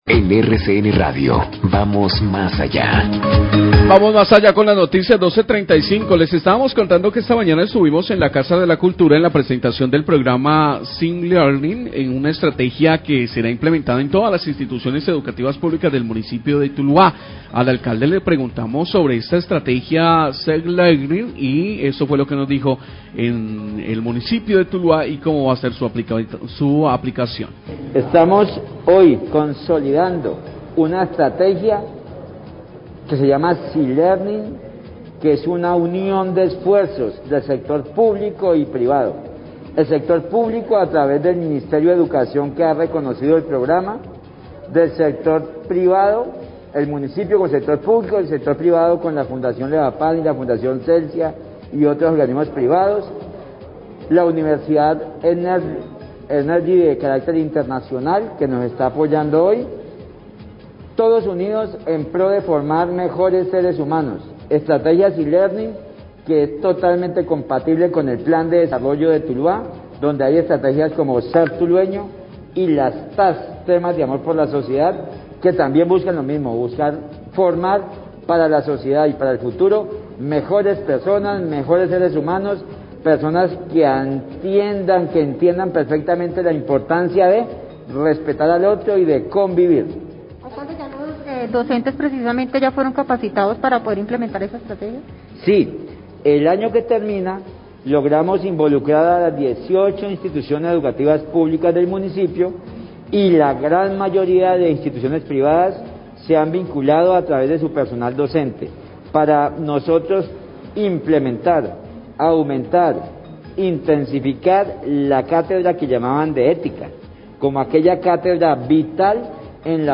Alcalde Tuluá habla de la estrategia SEE Learnong apoyada por la Fundación Celsia
Radio